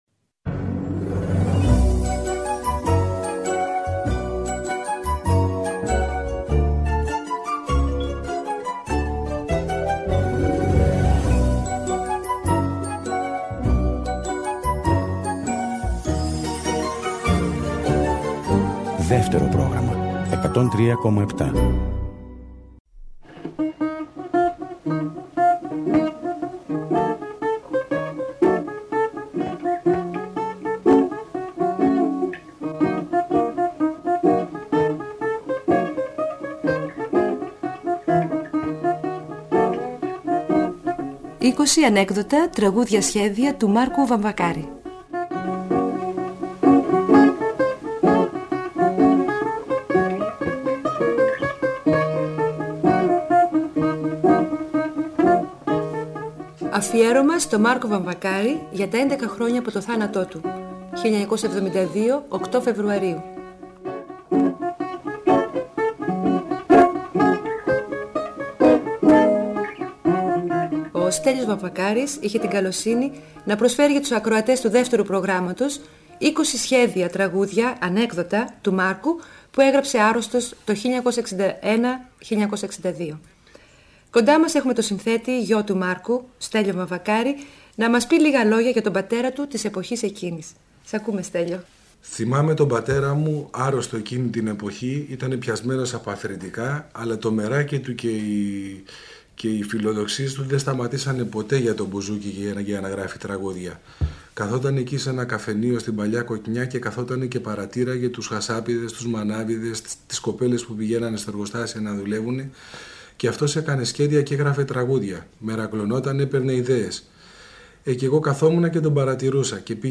ηχογραφημένα πρόχειρα από τον Μάρκο και τον Στέλιο Βαμβακάρη στο σπίτι τους